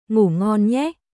Ngủ ngon nhéグー・ゴン・ニェーおやすみ（カジュアル）